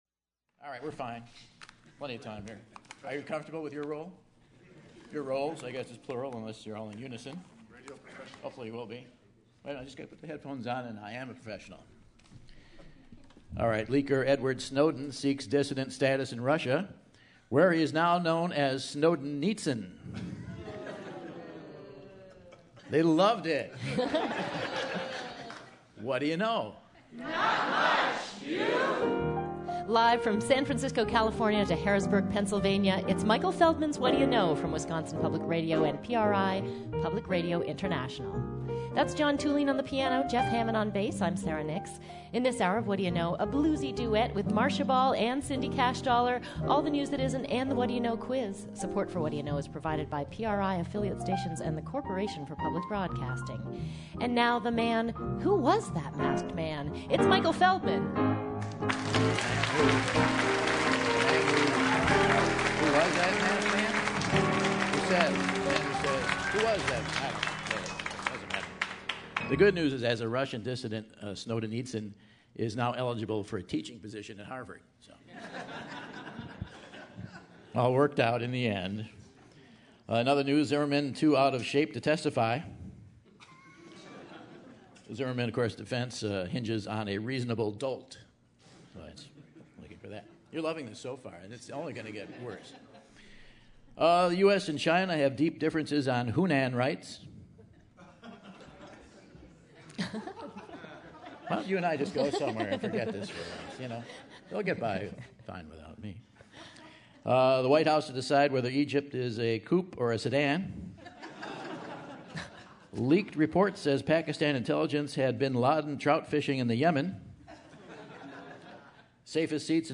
July 13, 2013 - Madison, WI - Monona Terrace | Whad'ya Know?